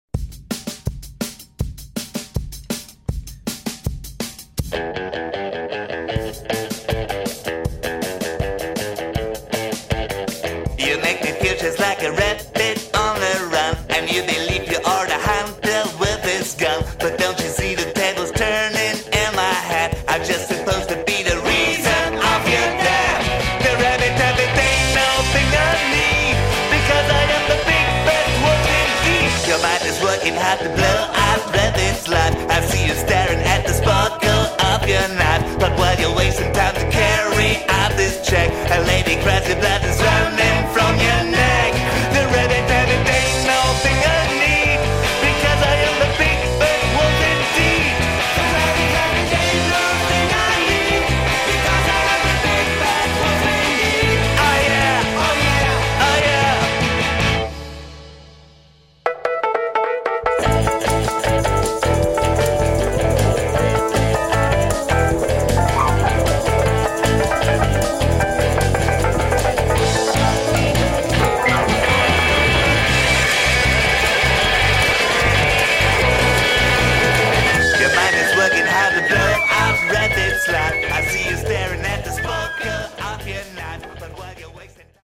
ld-voc., el-p.
voc., rh-g.
voc., bg.